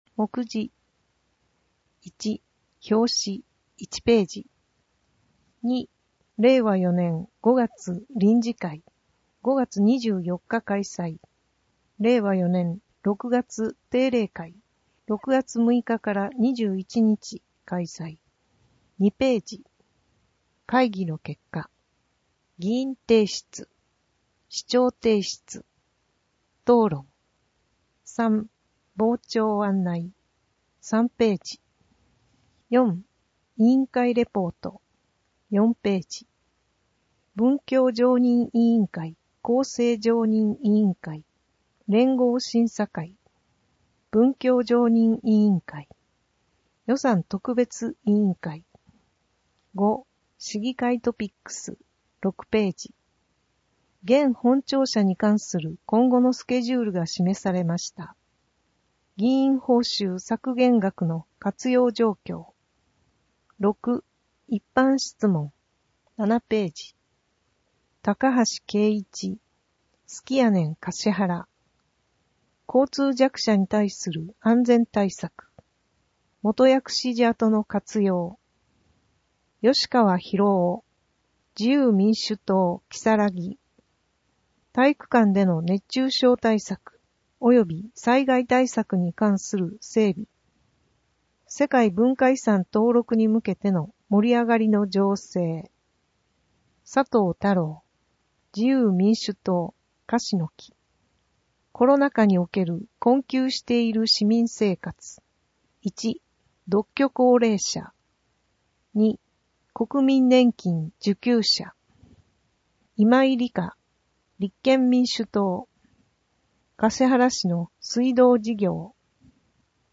かしはら市議会のいま222号 (PDFファイル: 11.3MB) 音訳データ かしはら市議会のいま第222号の音訳をお聞きいただけます。 音訳データは、音訳グループ「声のしおり」の皆さんが音訳されたものを使用しています。